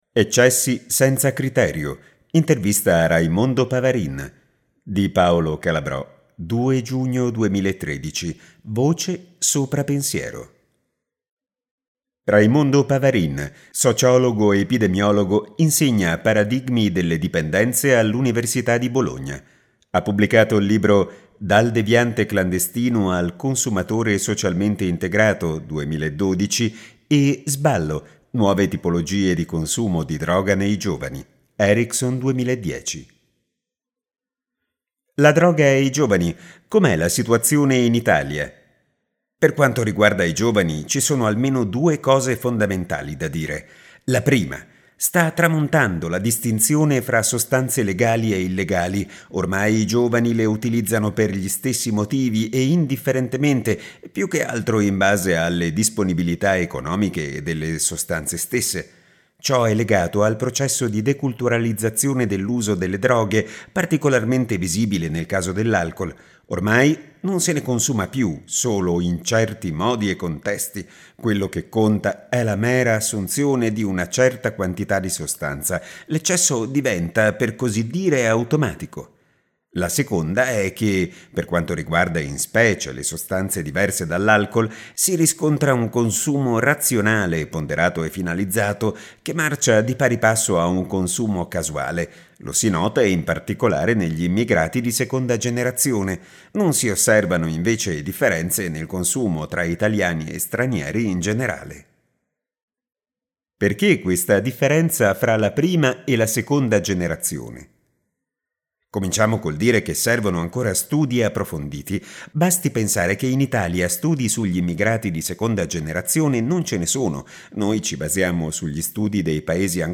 292_Interviste_EccessiSenzaCriterio.mp3